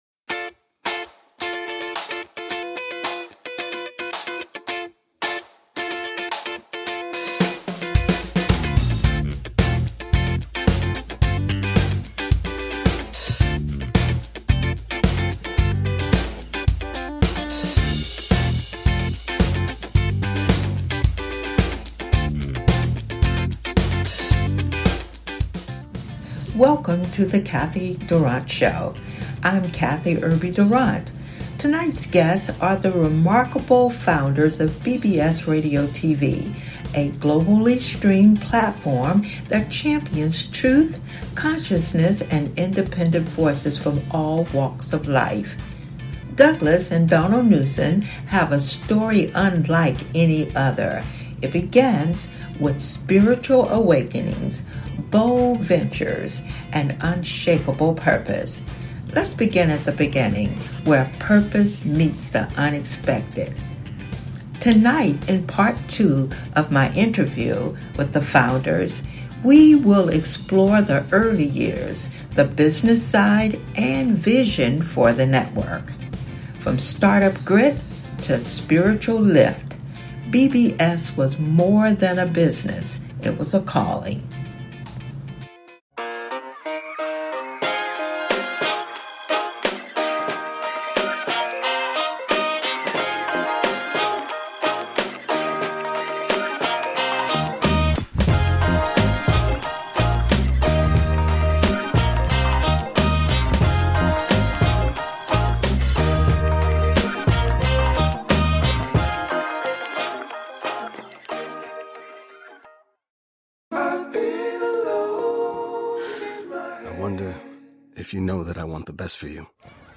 Headlined Show